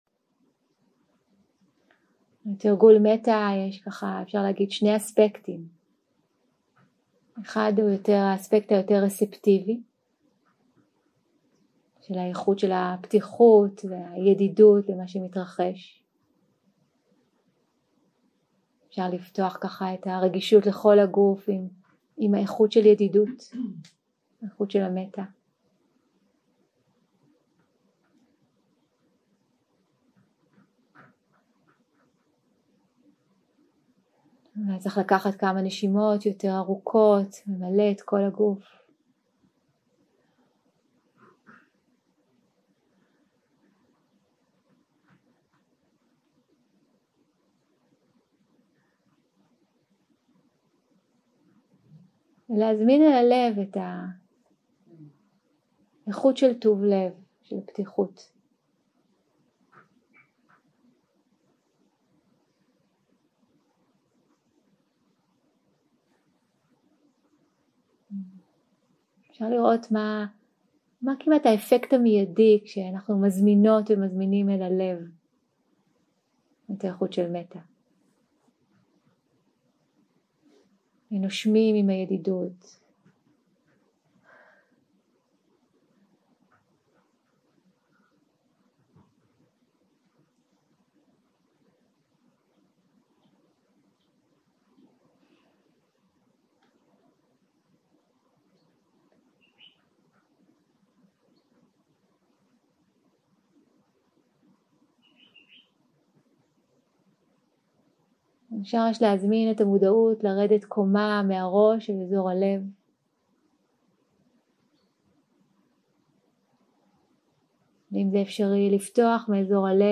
Dharma type: Guided meditation שפת ההקלטה